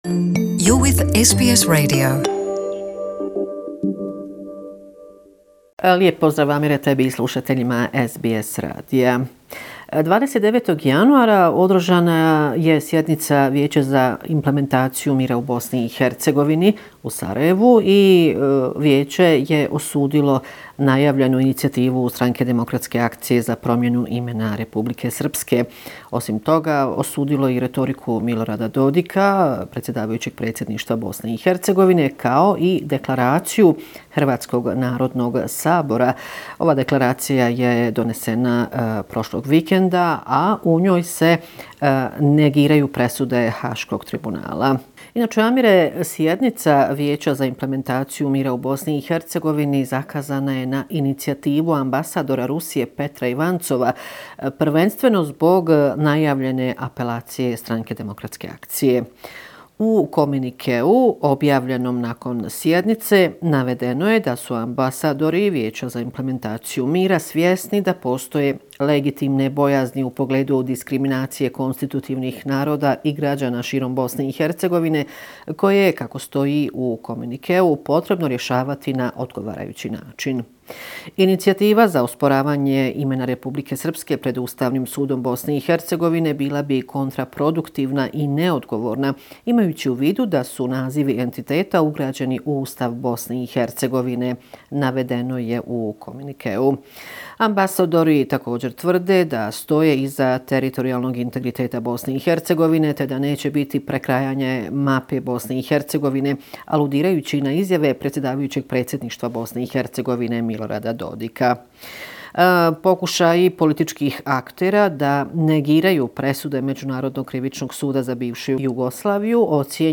Bosnia and Herzegovina, weekly report, February 3, 2019